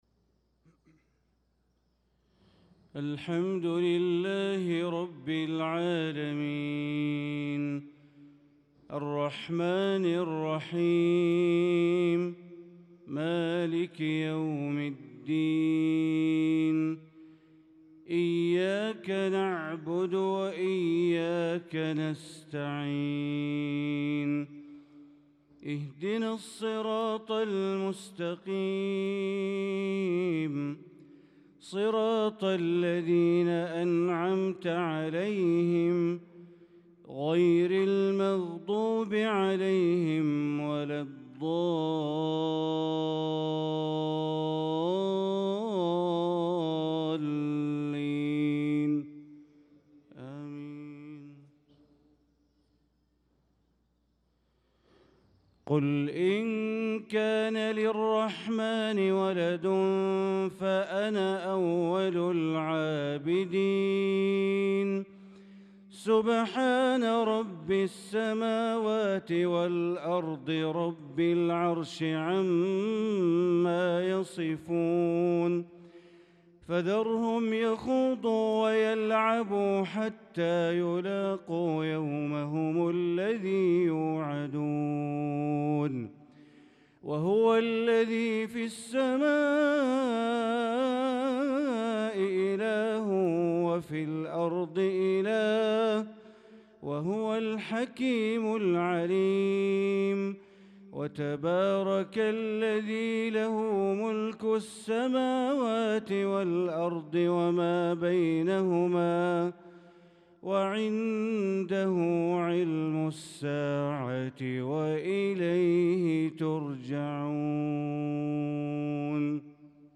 صلاة المغرب للقارئ بندر بليلة 17 شوال 1445 هـ